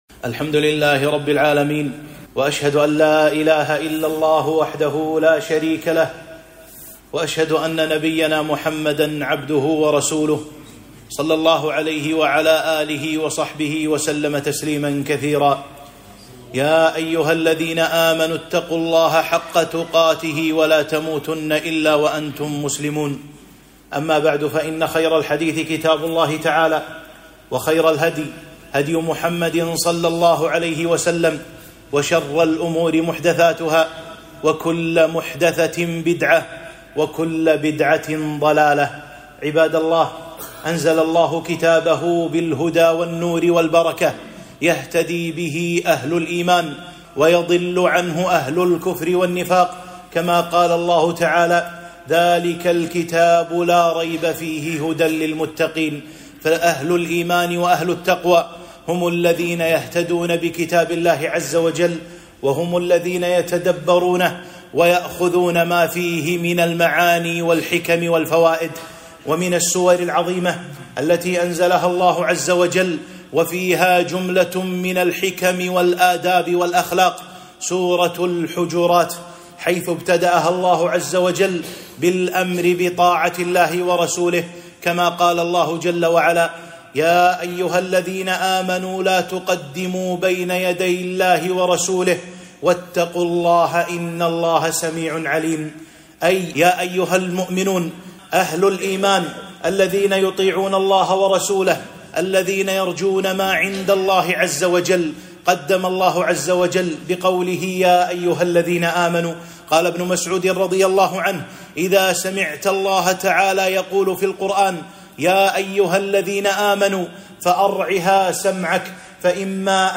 1- خطبة - فوائد ووقفات مع سورة الحجرات (الوقفة الأولى)